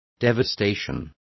Complete with pronunciation of the translation of devastation.